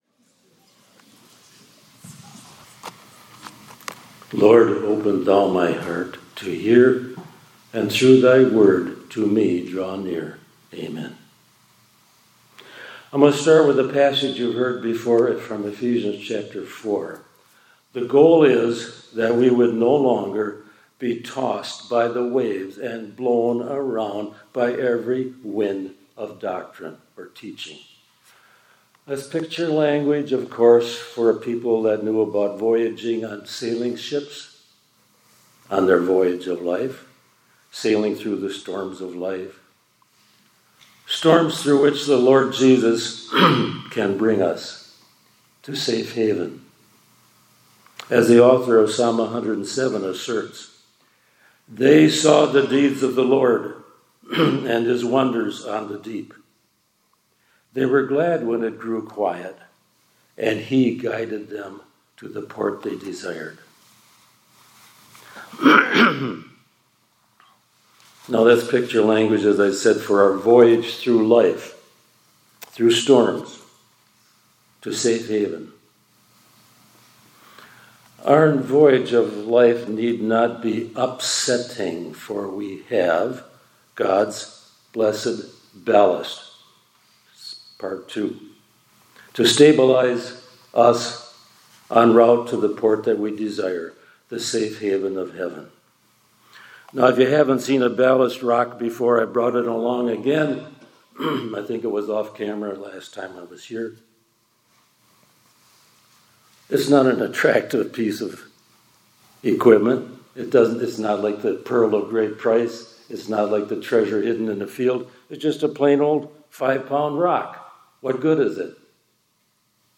2025-10-23 ILC Chapel — God’s Blessed Ballast, Pt 2